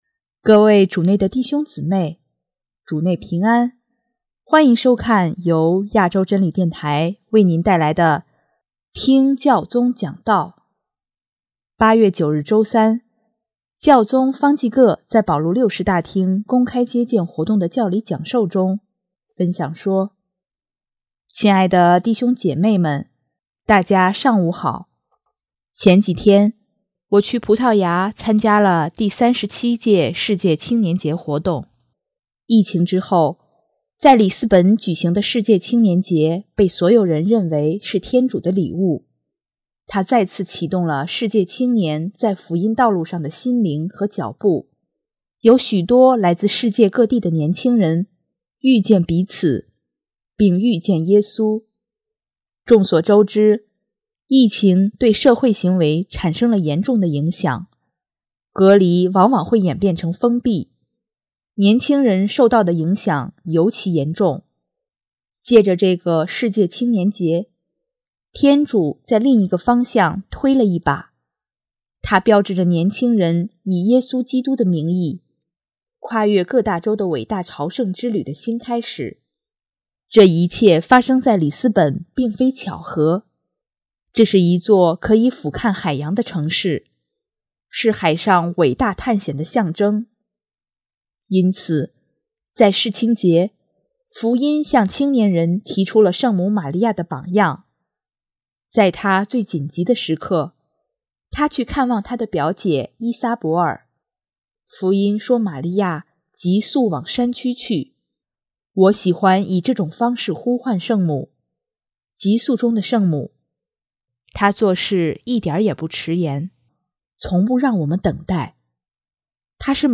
8月9日周三，教宗方济各在保禄六世大厅公开接见活动的教理讲授中，分享说：